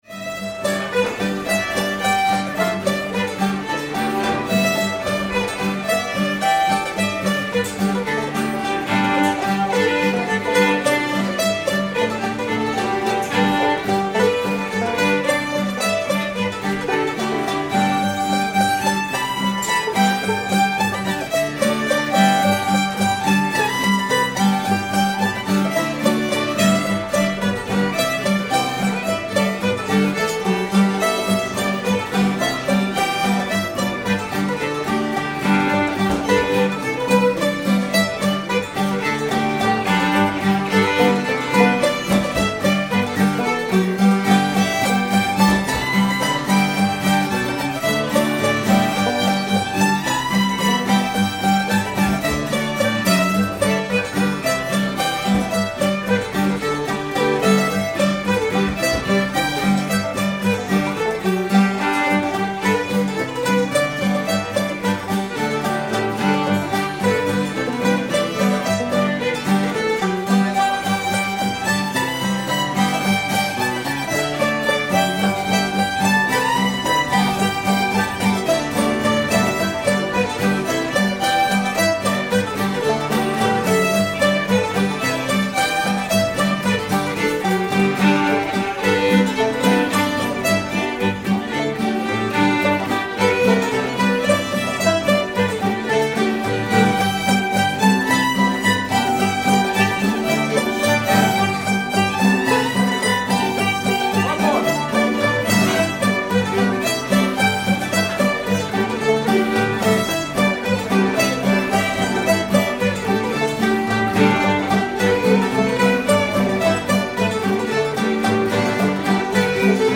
step around johnny [D]